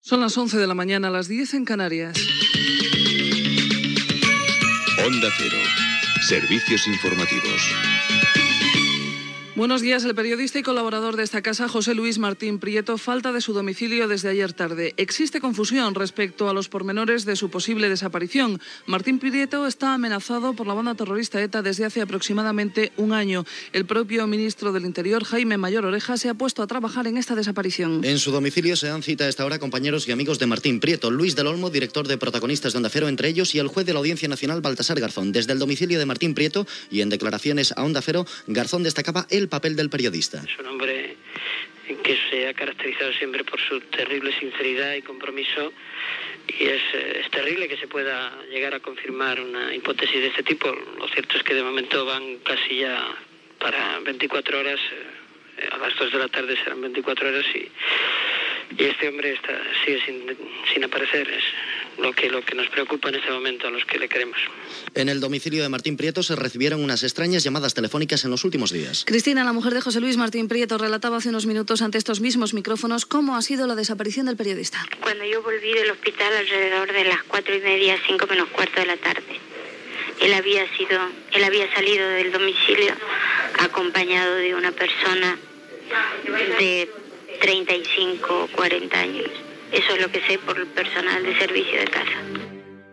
Hora, careta, informació de la desaparició del periodista José Luis Martín Prieto (finalment va ser un fals segrest)
Informatiu